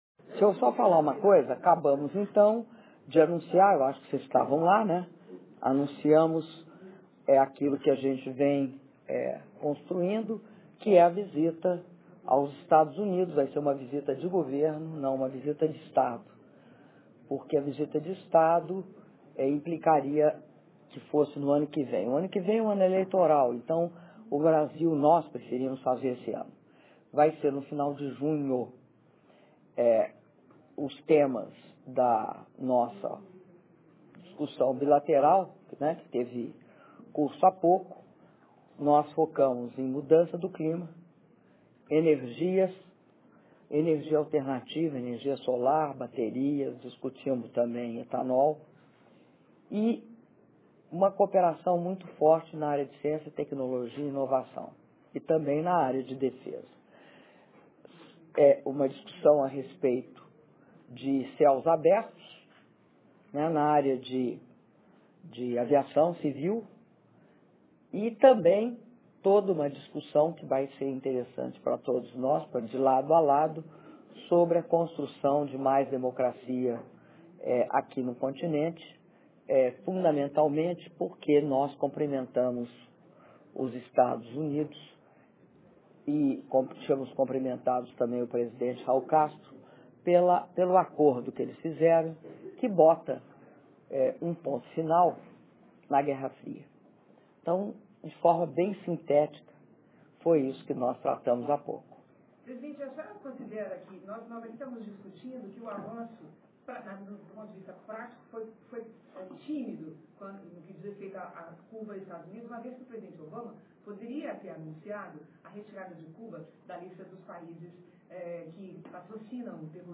Áudio da entrevista coletiva concedida pela Presidenta da República, Dilma Rousseff, após cerimônia da VII Cúpula das Américas - Cidade do Panamá/Panamá (20min13s)